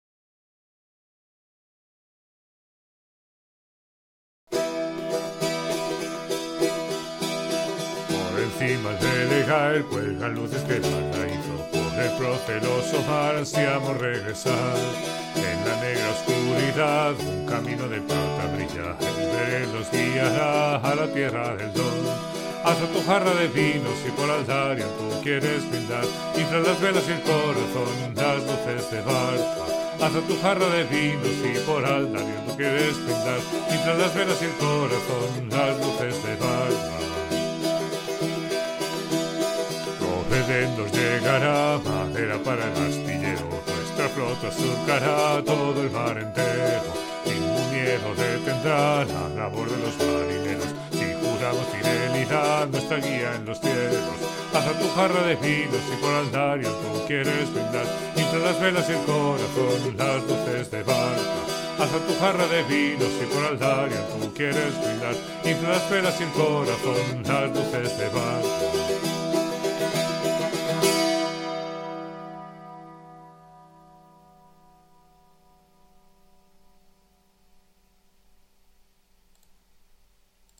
Tradicional folk irlandesa Em………………………………D……………………………..